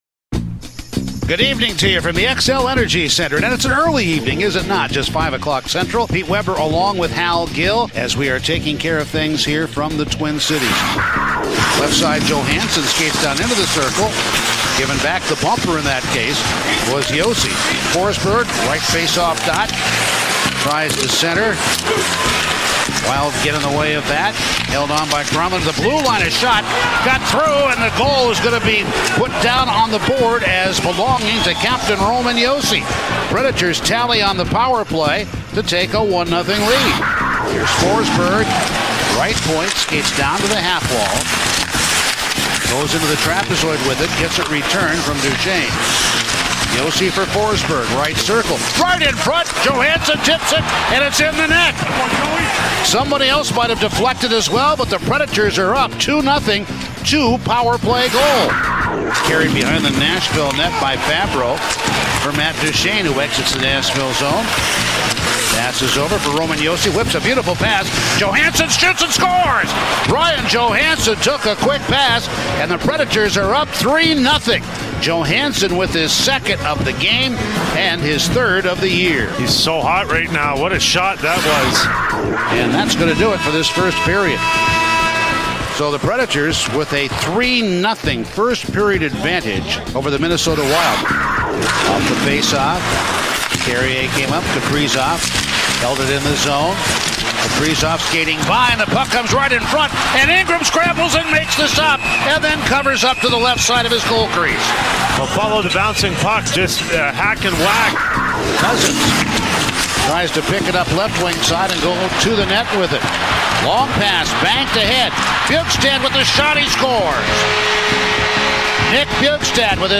Full radio highlights from the Preds' 5-2 win in Minnesota as heard on 102.5 The Game on Sunday, October 24